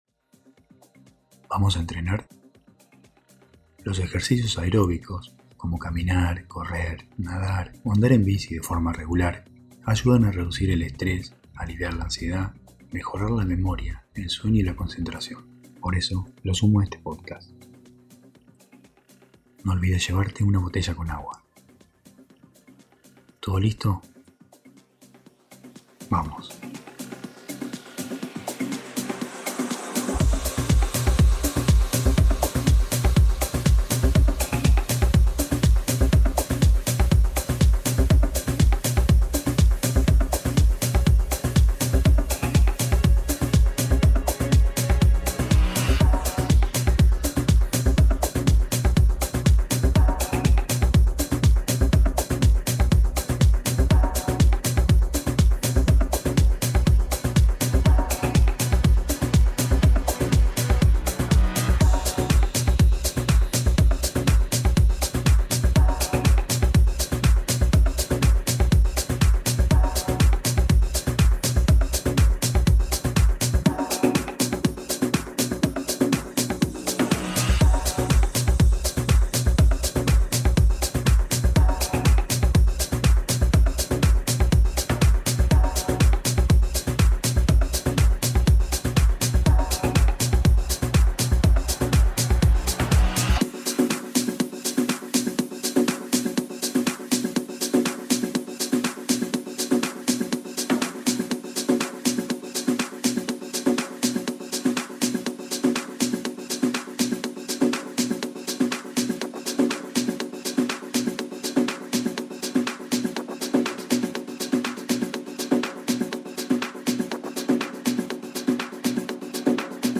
Entrenemos Juntos - Mood: Progressive
Acompañamiento motivacional.¿Vamos a entrenar?